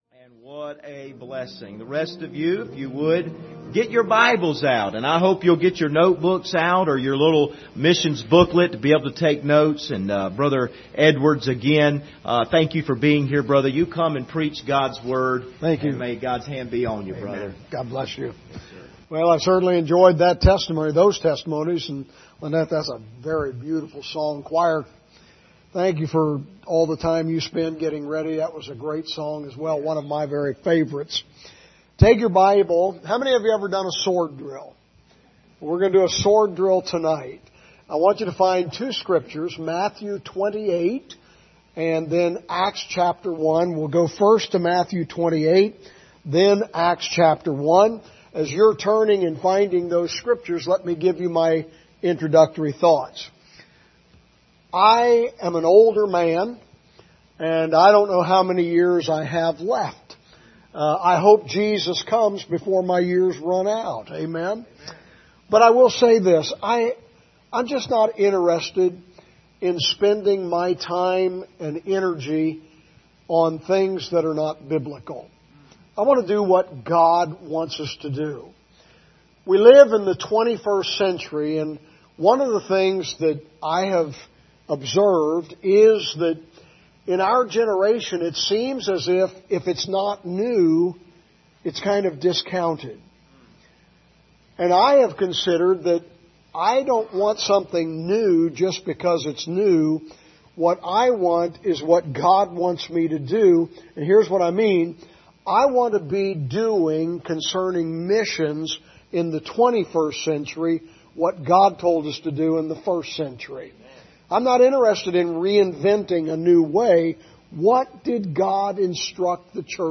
Series: 2022 Missions Conference Passage: Matthew 28:19-20 Service Type: Sunday Evening